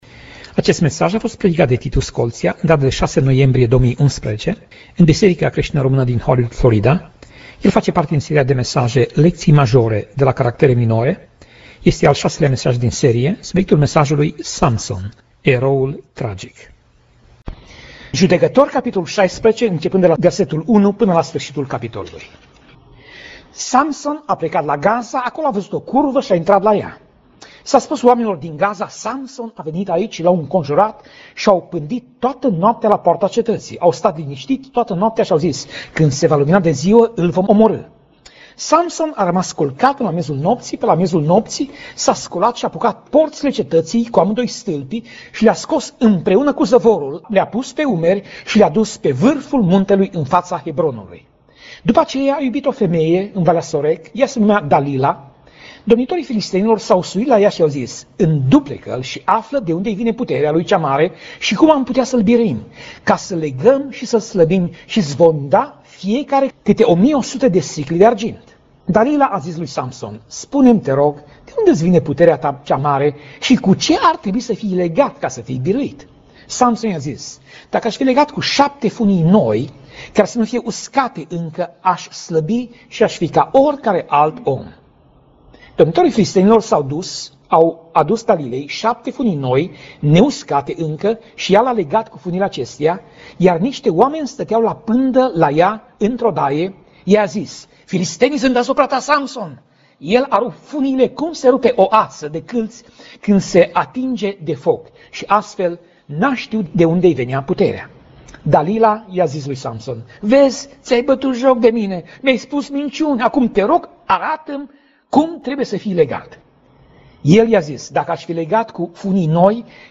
Pasaj Biblie: Judecatorii 16:1 - Judecatorii 16:31 Tip Mesaj: Predica